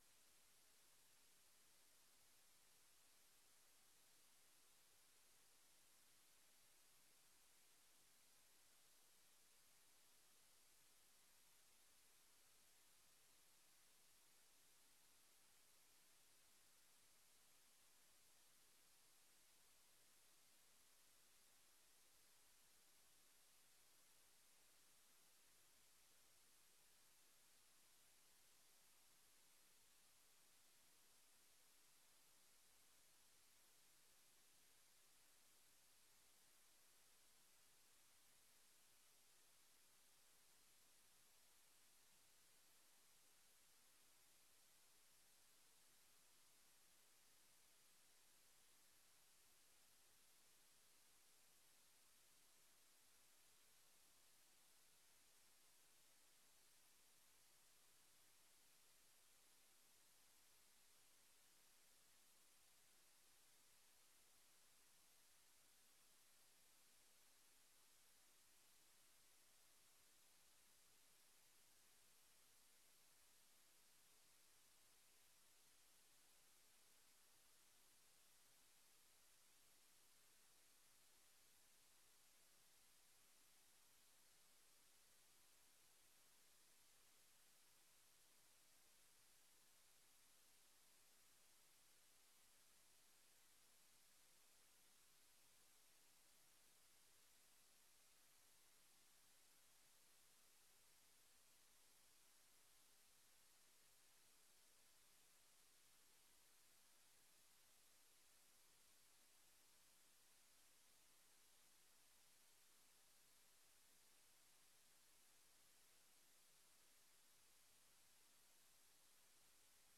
Raadsvergadering 25 september 2025 20:00:00, Gemeente Oude IJsselstreek
Locatie: DRU Industriepark - Conferentiezaal